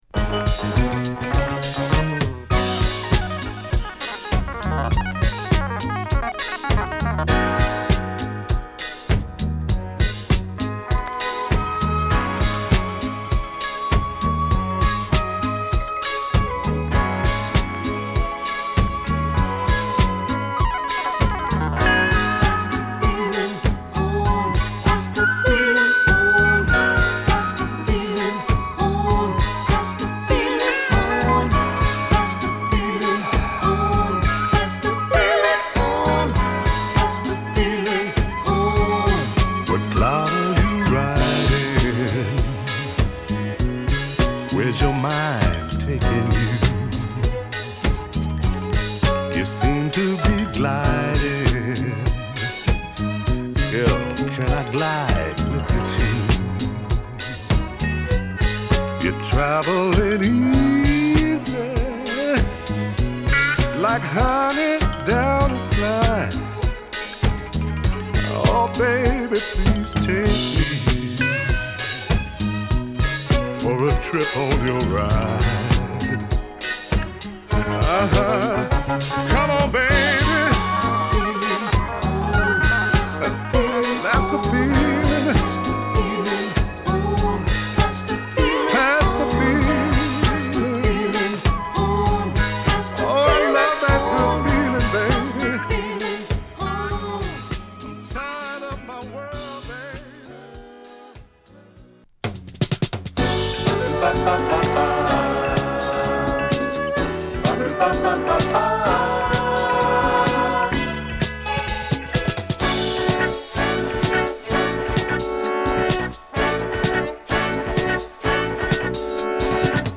ゆったりしつつもファットなダンスビートに小気味良い木琴やストリングスが絡むトラックでソウルフルなヴォーカルを聴かせる
伸びやかなホーンが絡む、よりダンサブルな